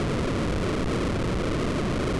push_stone.wav